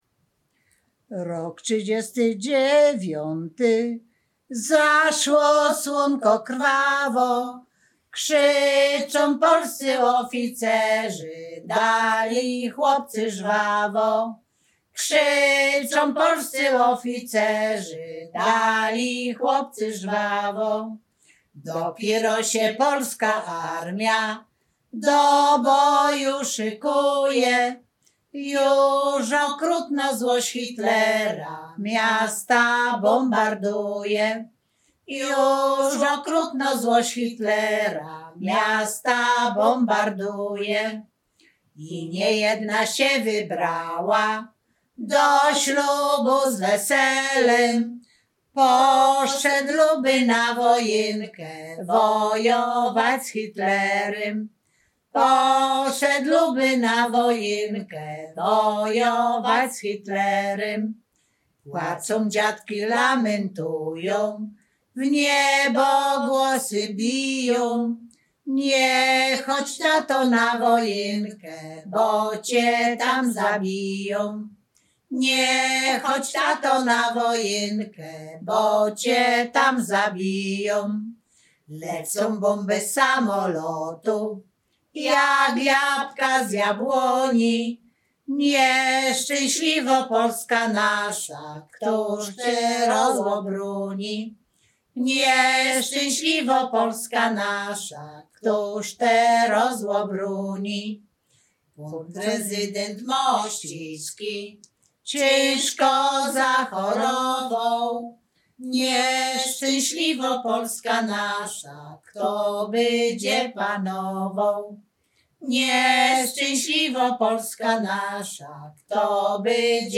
Śpiewaczki z Chojnego
województwo łódzkie, powiat sieradzki, gmina Sieradz, wieś Chojne
wojenkowe rekruckie